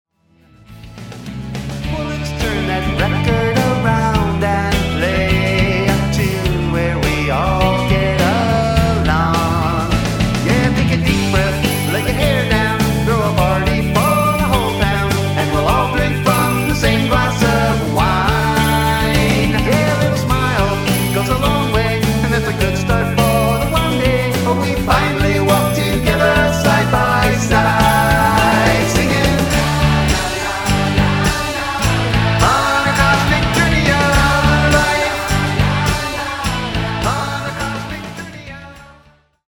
IF YOU'RE A RETRO POP/ROCK MUSIC LOVER,
The end result is mostly upbeat tunes